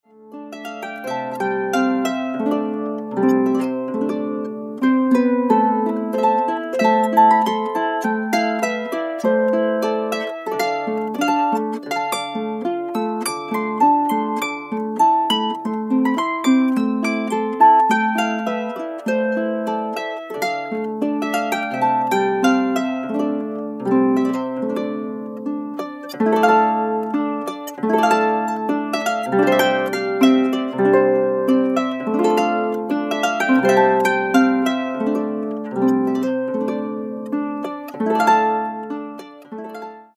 (Celtic harp)  2'152.06 MB1.70 Eur